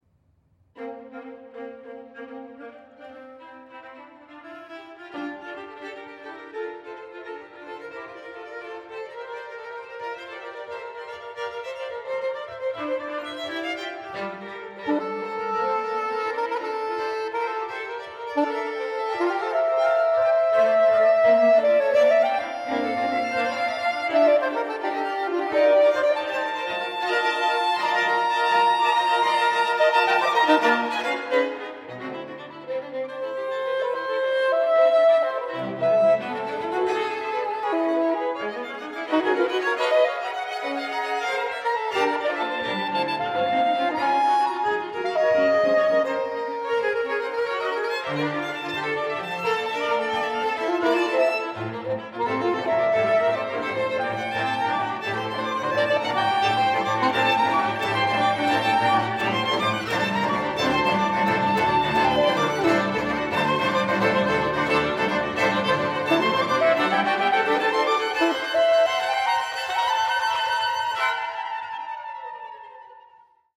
Longtime Duke University string quartet in residence
saxophone